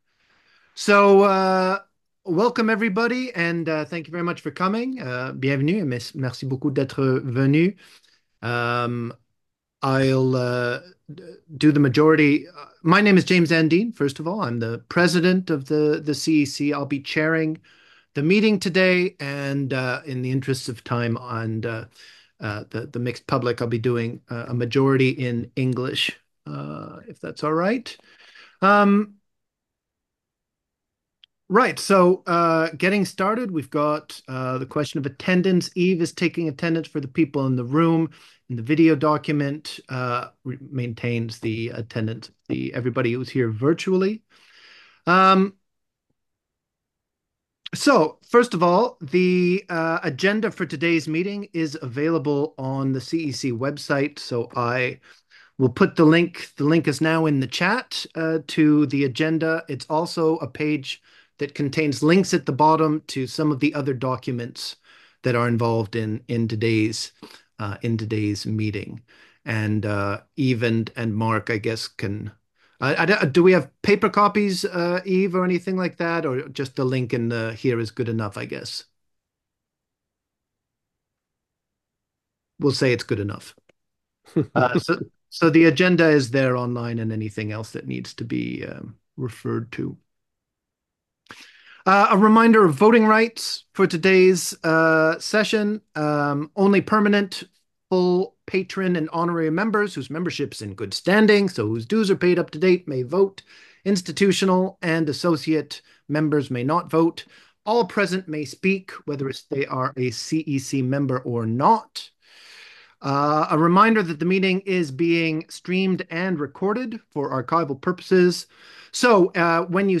The AGM was a hybrid event.
A video webcast was transmitted from the venue to allow active participation by those unable to attend in person.